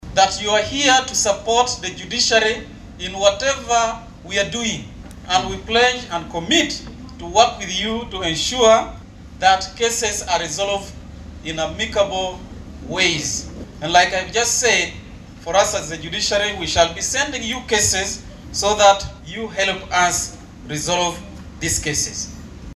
Voice-1: Justice Odoki talking about working with the traditional chiefs.